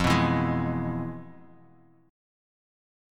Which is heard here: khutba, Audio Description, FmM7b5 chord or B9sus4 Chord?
FmM7b5 chord